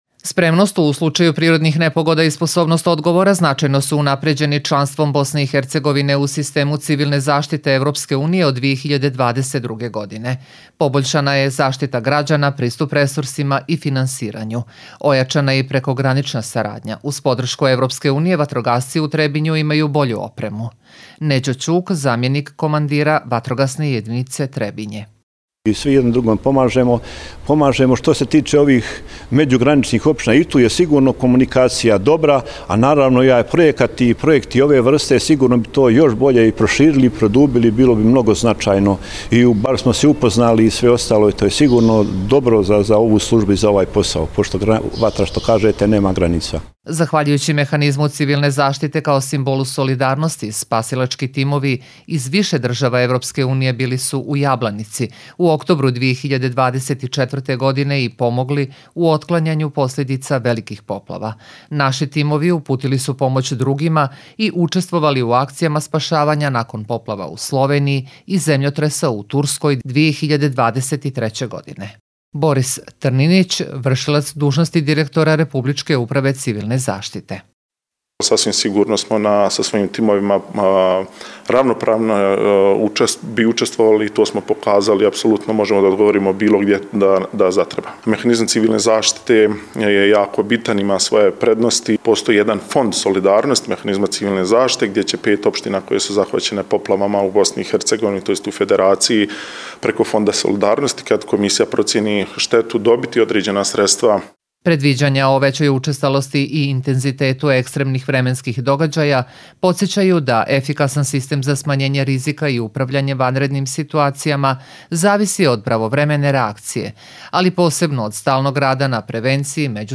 Radio reportaža